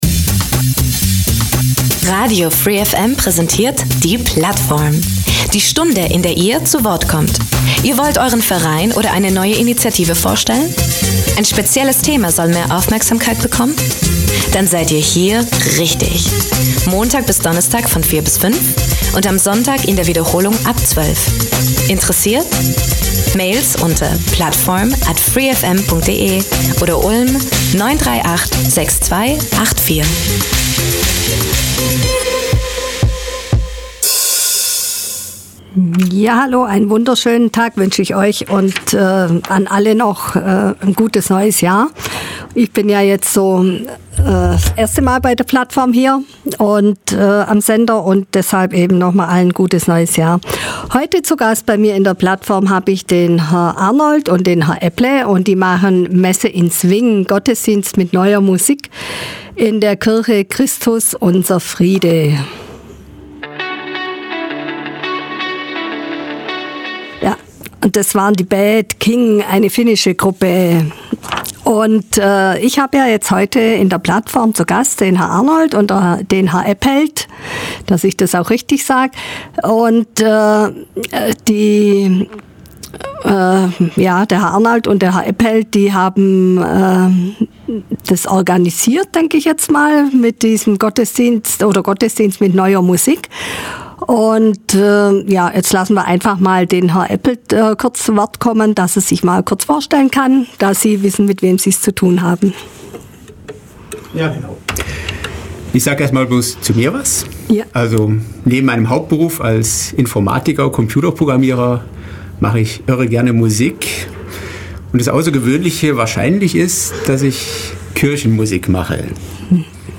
Die „Messe in Swing“ von Siegfried Arnold ist ein fünfsätziges Werk für Chor, Solisten und Band, dem die muskalische Verbindung zwischen Klassik und Swing gelingt.